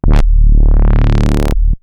Roland A D1.wav